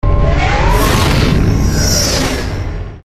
Das Geschütz eines Nakai-Raumschiffs in Youngs Traum
SGU_2x06_Nakai-Schiffswaffe.mp3